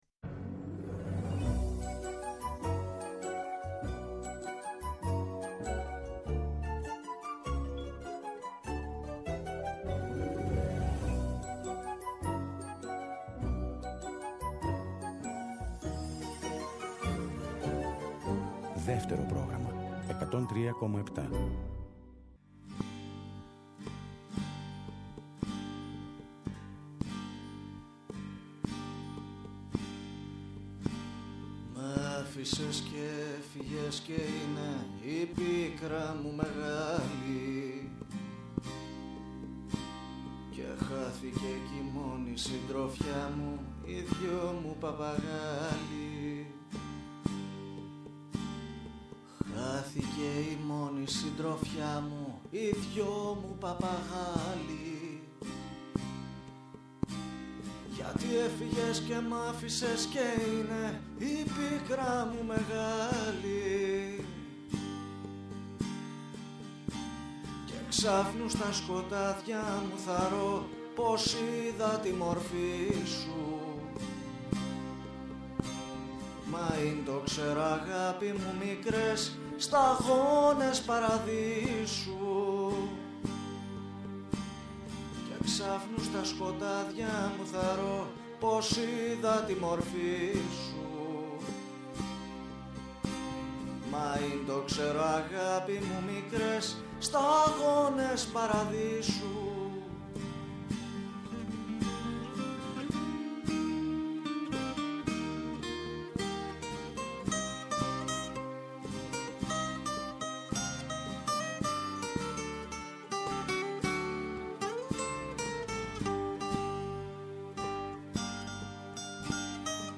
ήταν φιλοξενούμενος στο στούντιο του Δευτέρου Προγράμματος
Συνεντεύξεις